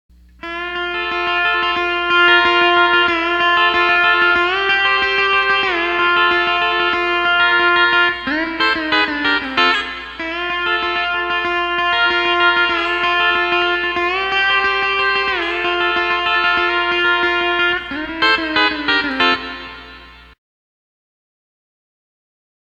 Steel Guitar Tab / Lessons
E9th - Raising The 4th String 1/2 Tone Example #4 - Unison Finger Roll - Key of F Tab